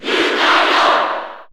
Crowd cheers (SSBU) You cannot overwrite this file.
Lucario_Cheer_Japanese_SSB4_SSBU.ogg